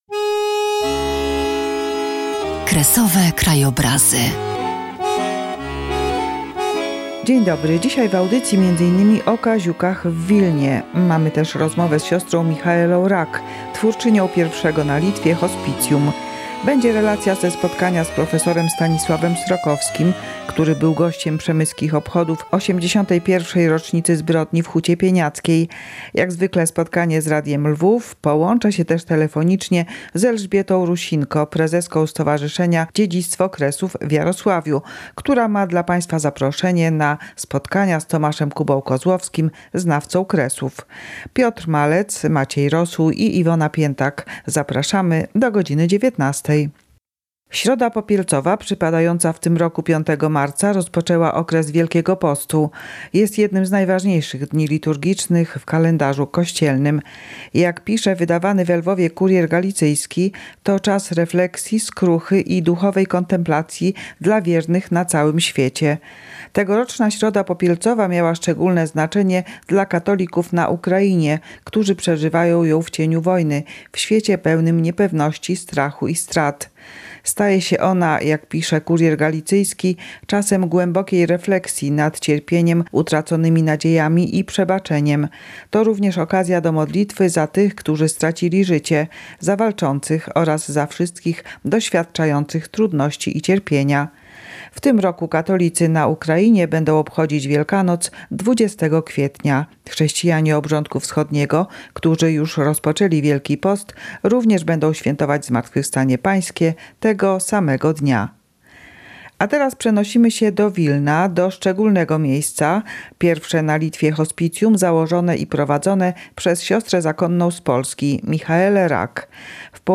W audycji: rozmowa